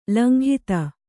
♪ langhita